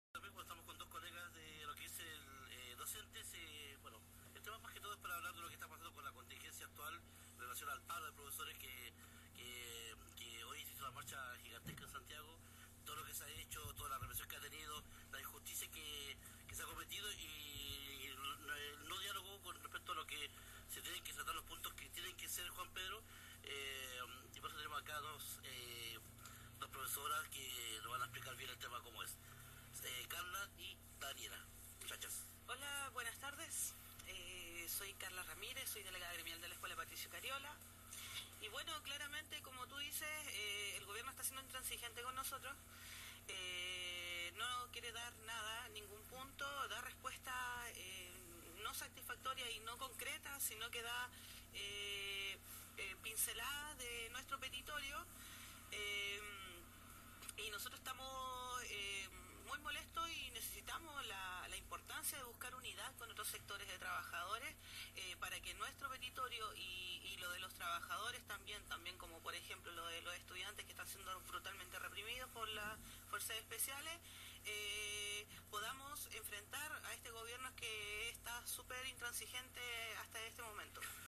Entrevista radial a profesoras de la Agrupación Nuestra Clase a raíz del Paro docente